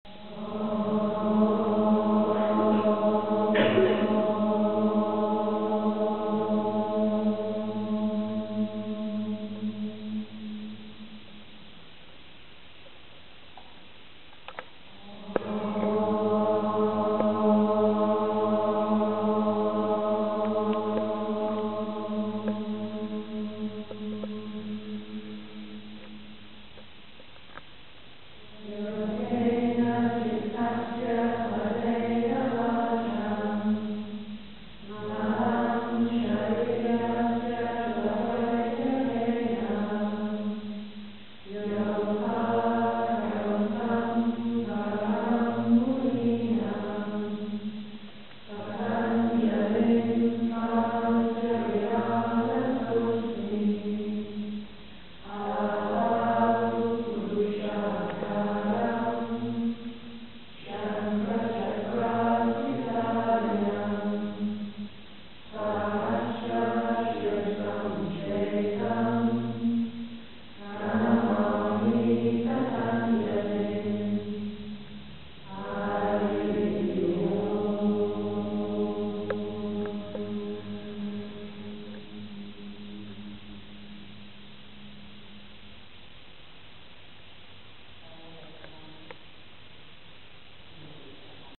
Theo truyền thống của Iyengar yoga, trước khi bắt đầu tập cả lớp phải tụng một bài mantra ngắn tưởng niệm đạo sư Patanjali bày tỏ sự tôn kính và sự sẳn sàng cho tính khiêm nhường và tấm lòng rộng mở
Invocation-Chant-to-Patanjali.m4a